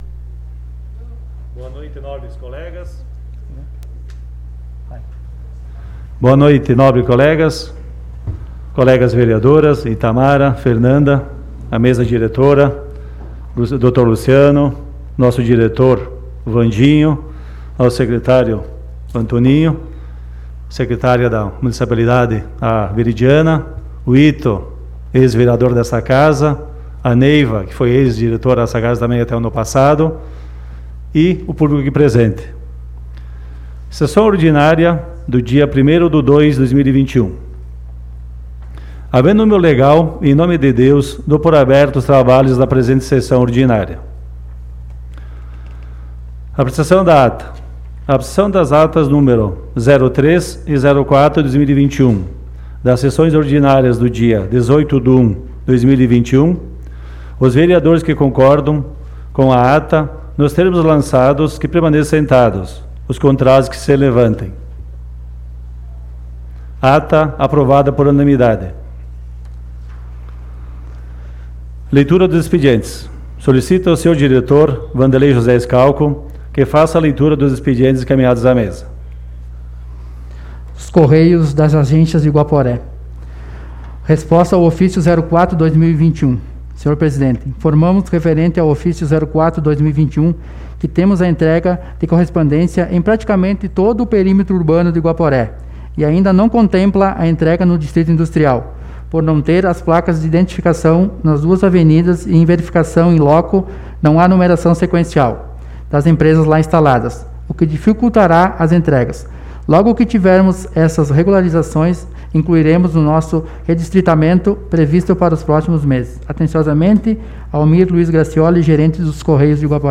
Sessão Ordinária do dia 01 de Fevereiro de 2021 - Sessão 05